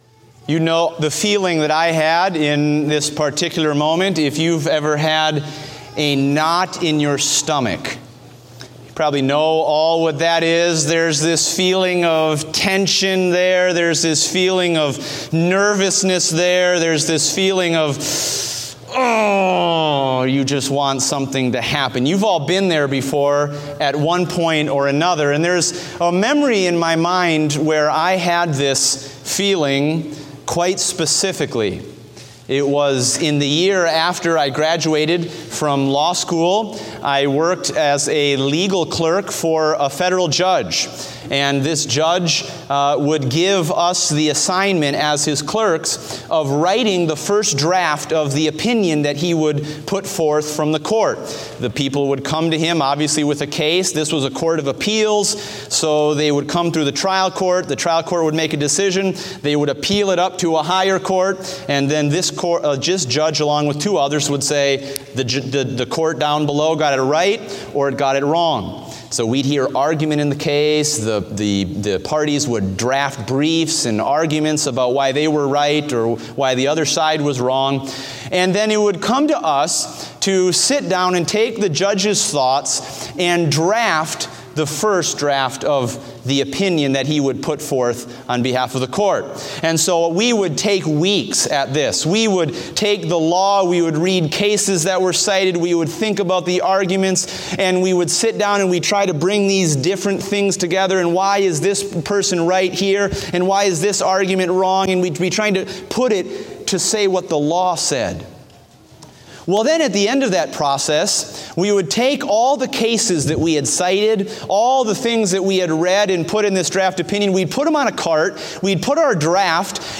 Date: May 22, 2016 (Morning Service)